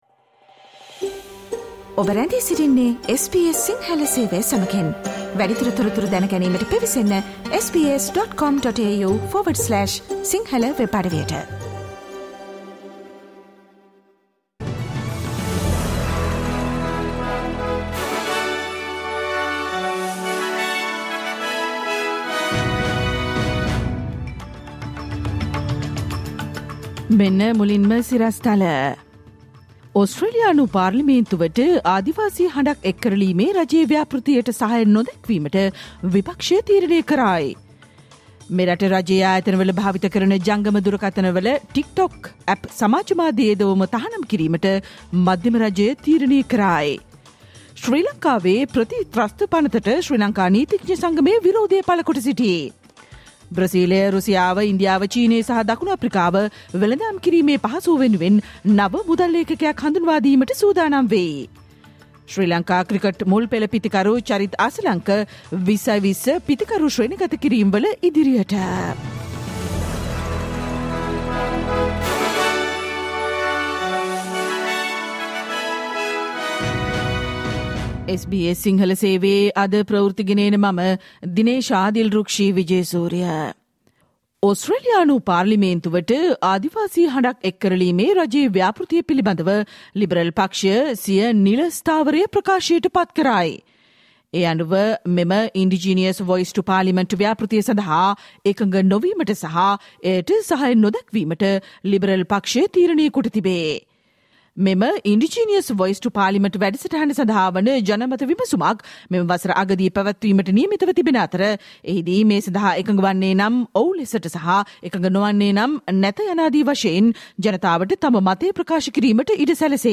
Listen to the SBS Sinhala Radio news bulletin on Thursday 06 April 2022